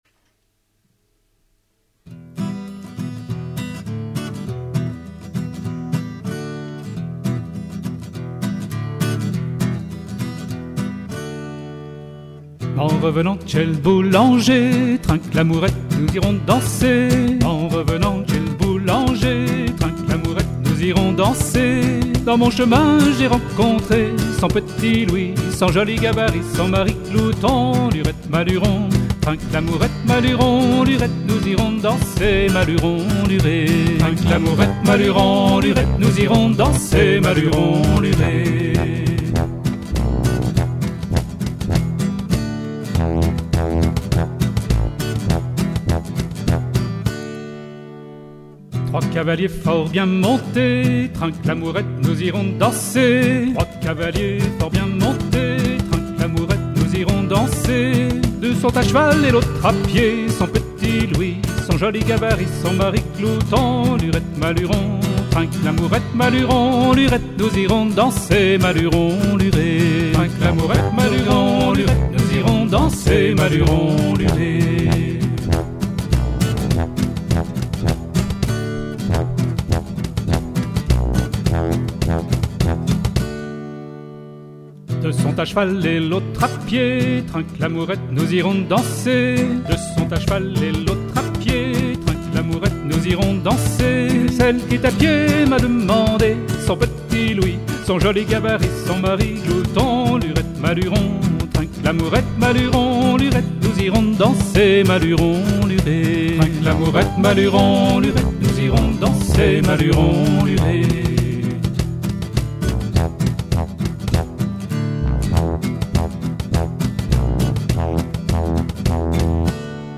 Traditionnel (Québec)